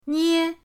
nie1.mp3